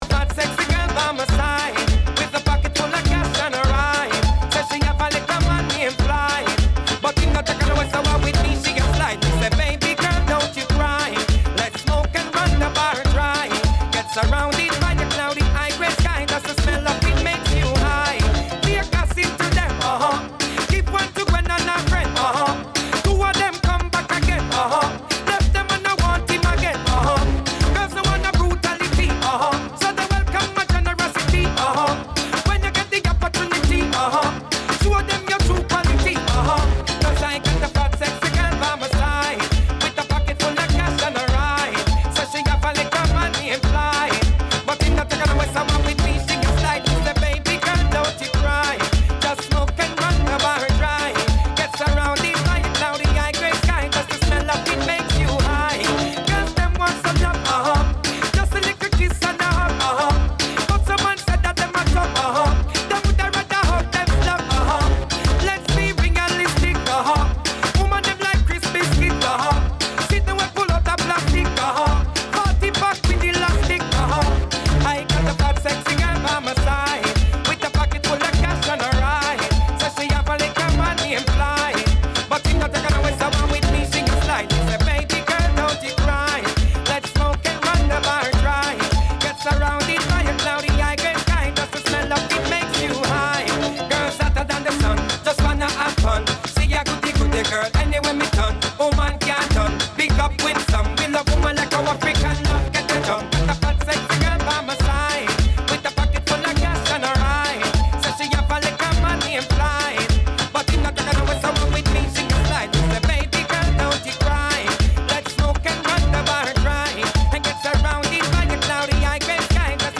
Ragga & Dancehall